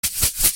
shake.mp3